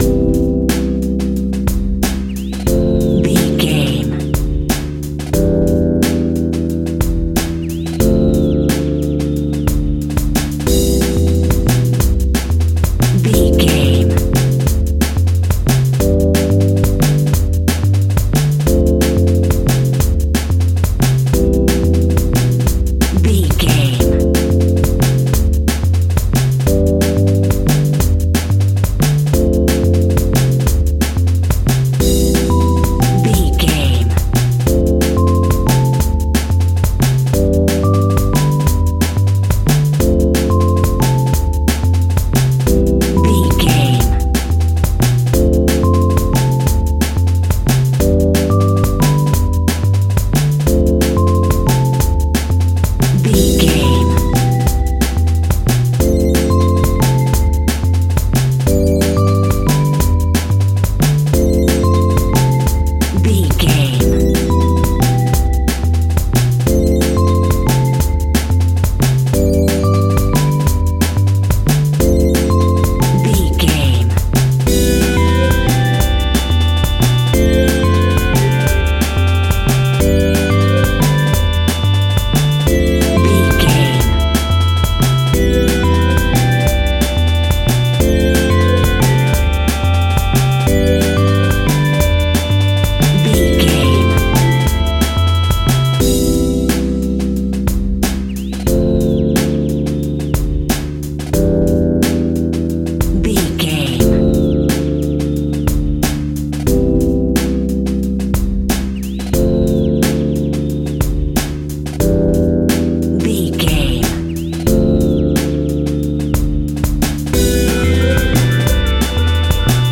Ionian/Major
Fast
energetic
hypnotic
industrial
frantic
drum machine
synthesiser
electronic
sub bass
synth leads
synth bass